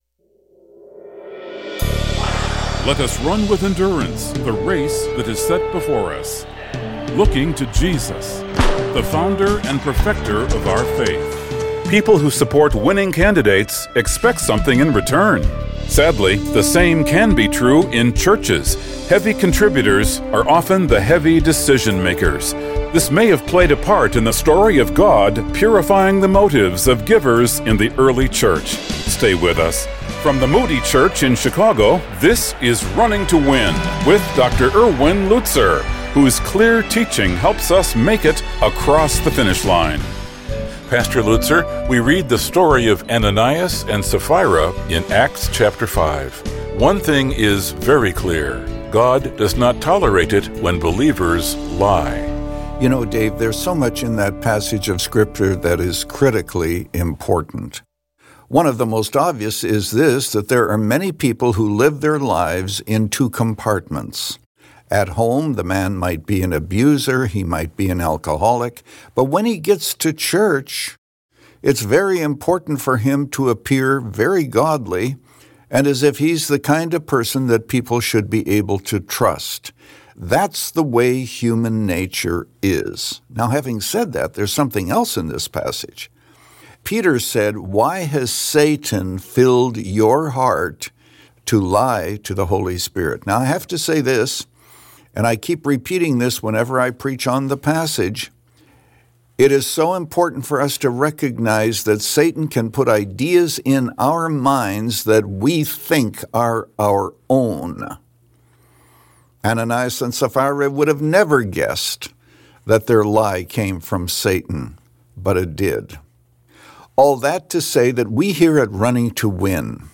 In this message, Pastor Lutzer shares two final lessons about giving our reputations to God. How would we view our reputations if God was first?